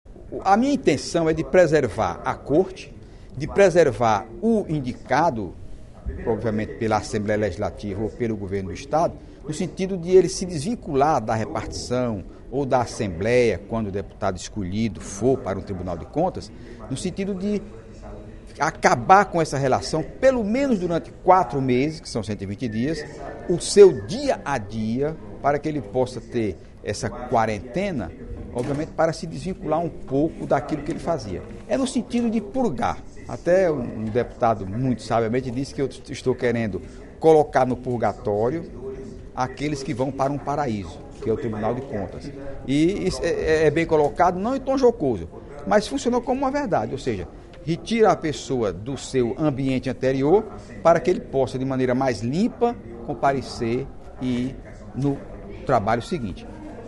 O deputado Heitor Férrer (PDT) destacou na sessão plenária desta sexta-feira (10/02) da Assembleia Legislativa o projeto de lei de sua autoria que estabelece o interstício de 120 dias para posse no cargo de conselheiro do Tribunal de Contas do Estado (TCE) e de conselheiro do Tribunal de Contas dos Municípios (TCM).